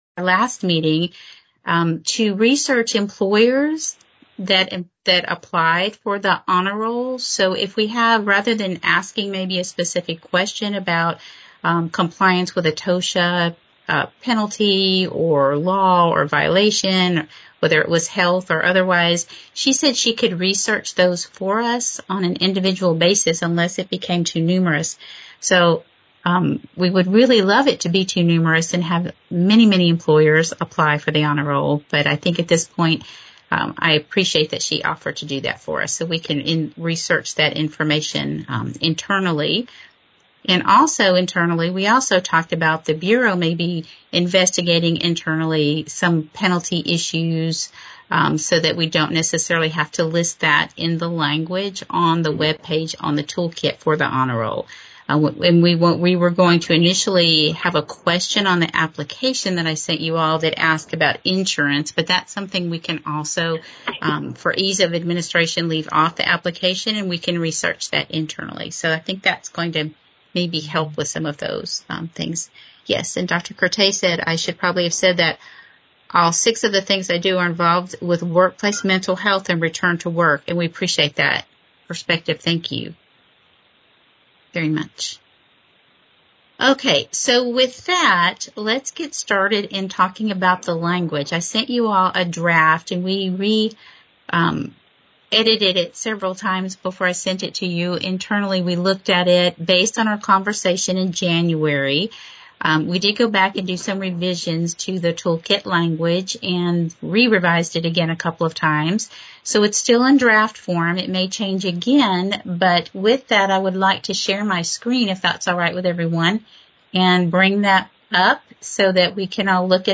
The meeting will be held virtually via Microsoft Teams.
The audio recording of this public meeting will be made available on our website.